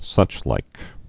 (sŭchlīk)